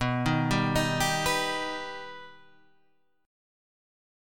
B Augmented